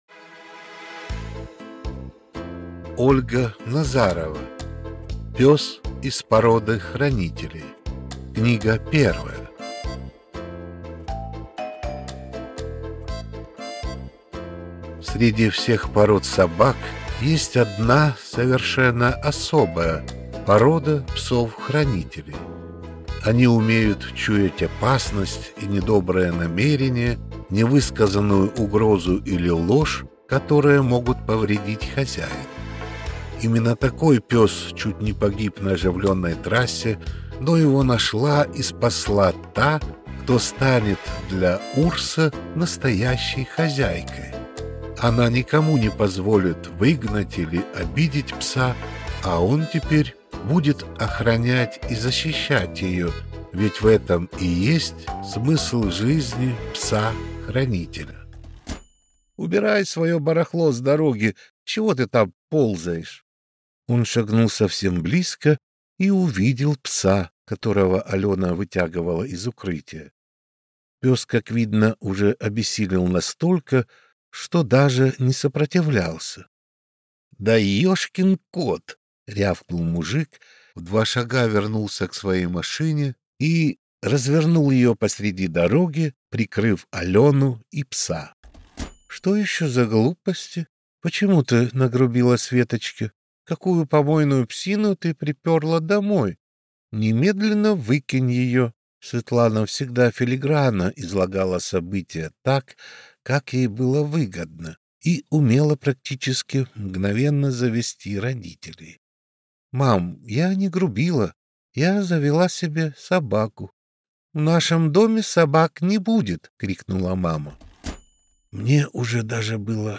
Аудиокнига Пёс из породы хранителей | Библиотека аудиокниг